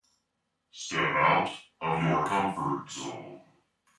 描述：电子或恍惚的好循环。机器人男声说：“走出你的舒适区”
Tag: ComfortZone舒适 电子 冥想 autovoice 效果 机器人 房子 配音步